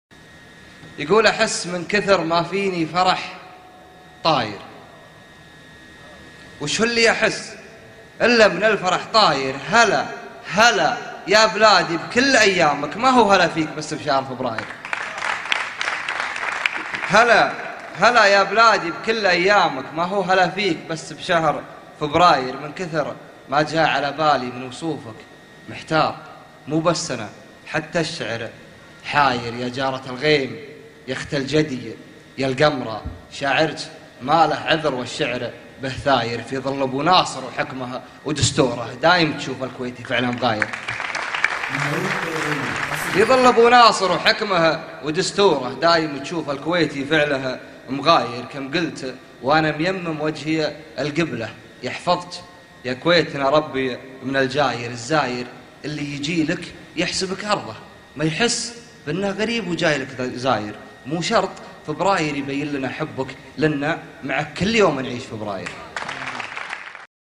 هلا يابلادي - امسيات ليالي فبراير